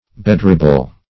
Bedribble \Be*drib"ble\